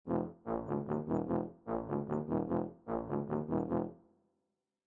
Download Tuba sound effect for free.
Tuba